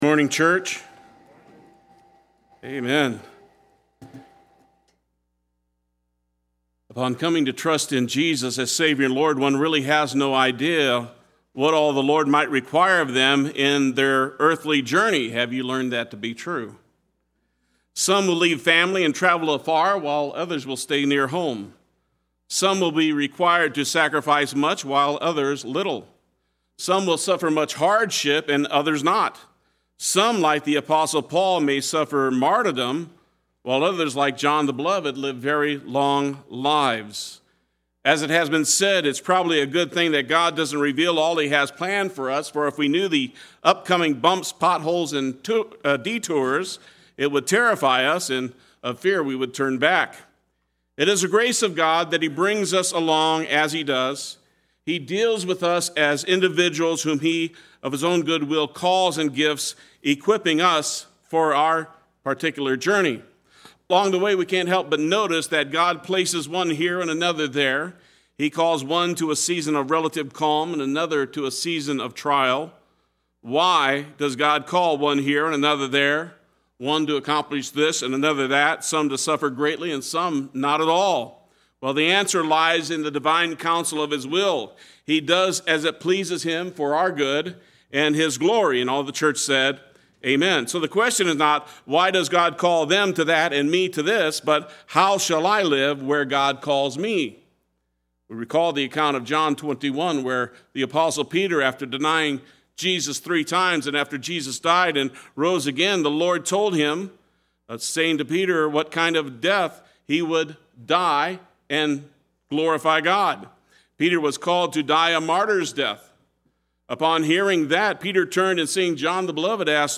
Play Sermon Get HCF Teaching Automatically.
Not Ashamed Sunday Worship